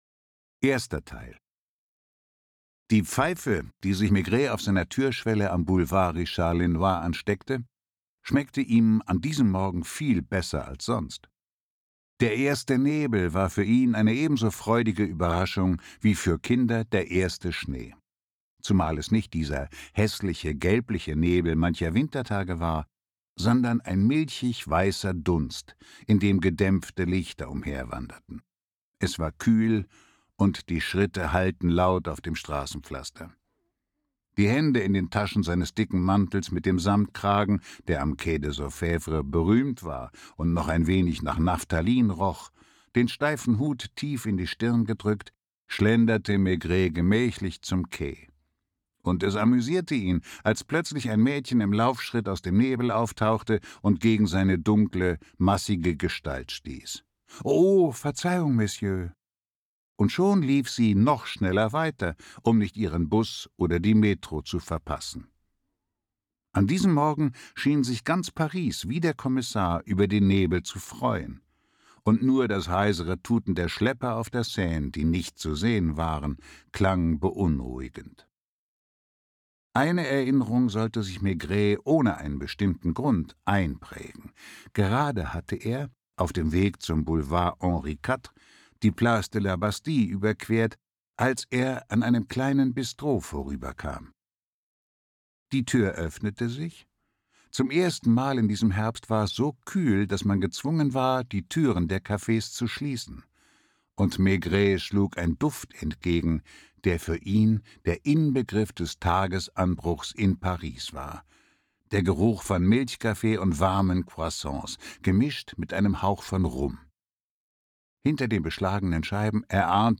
Walter Kreye (Sprecher)
Ungekürzte Lesung